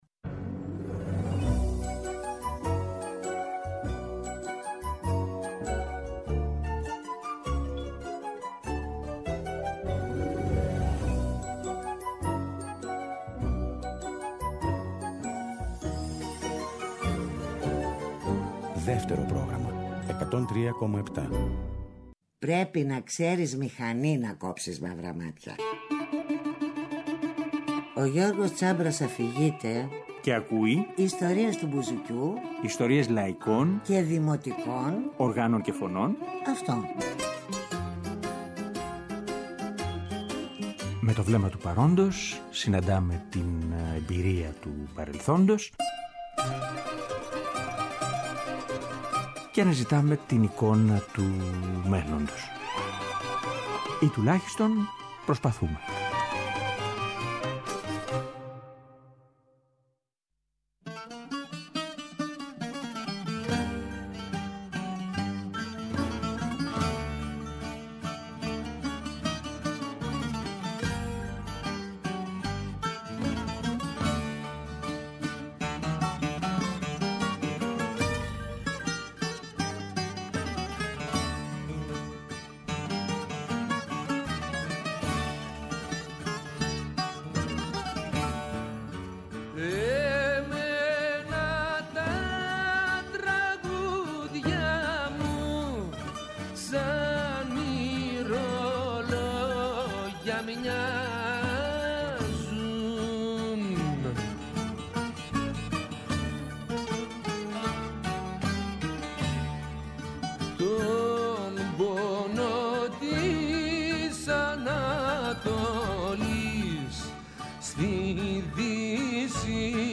στα στούντιο της ΕΡΑ
Τραγούδια γνωστά και λιγώτερο ακουσμένα.
μπουζούκι, μπαγλαμά
κρουστά.